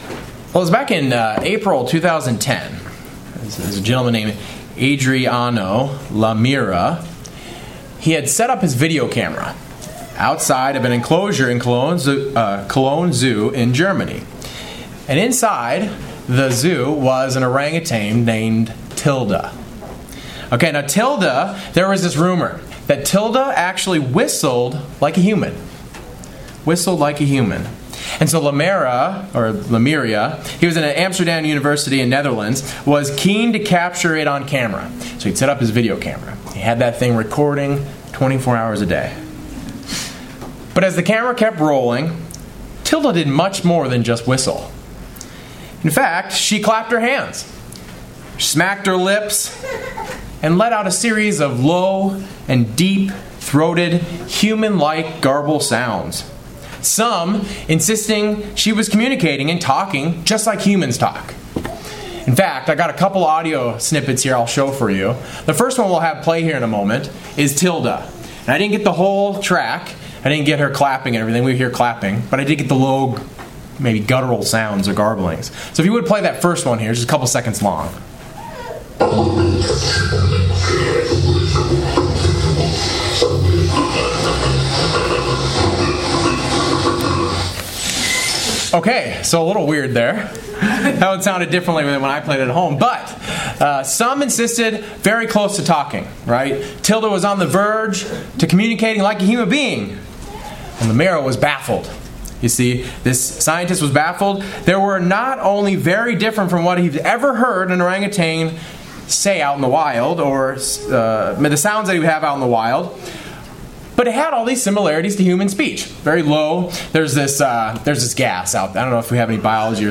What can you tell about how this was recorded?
Given in Cincinnati North, OH